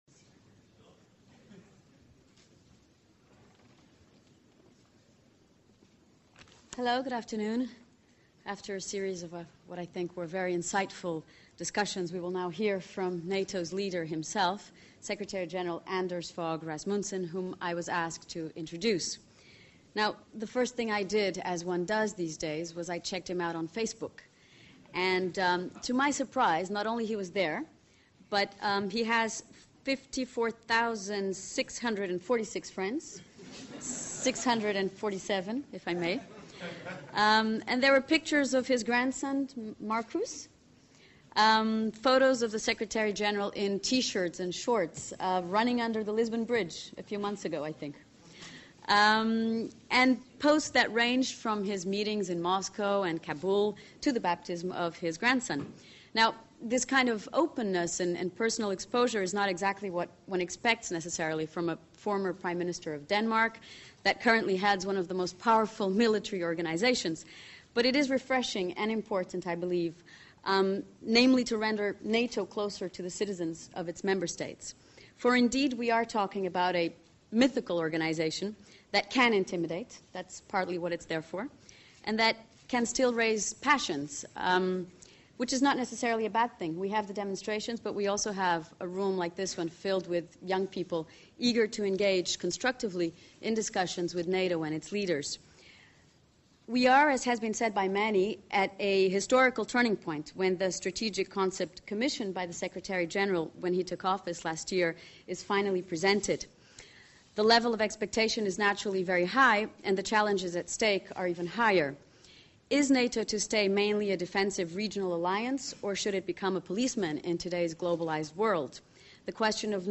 On 19 November, Secretary General Anders Fogh Rasmussen addressed Young Leaders at the 2010 Young Atlanticist Summit in Lisbon, Portugal, which is being run concurrently with the NATO Summit. In his keynote address, the Secretary General highlighted the complex security challenges of the 21st century, including weak states, terrorism, missile proliferation and cyber attacks.
Keynote address by NATO Secretary General Anders Fogh Rasmussen to the participants of the 2010 Young Atlanticist Summit in Lisbon, Portugal